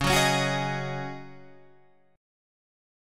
C#sus2sus4 chord